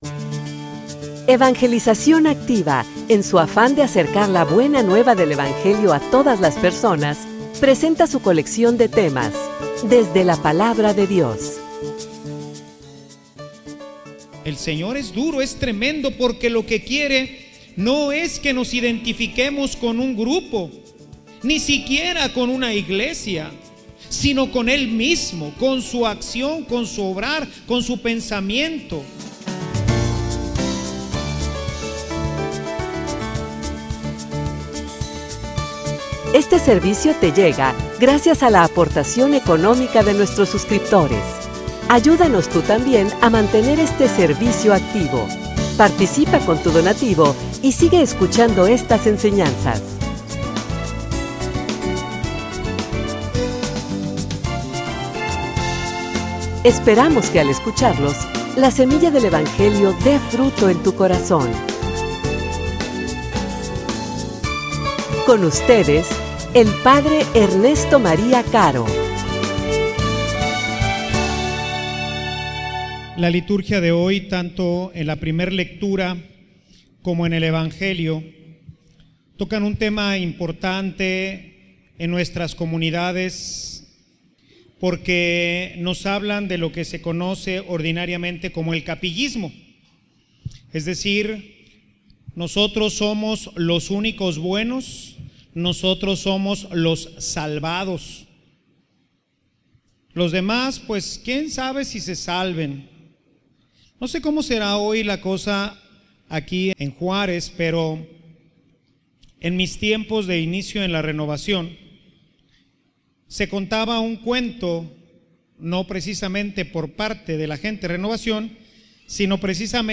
homilia_Y_tu_a_quien_sigues.mp3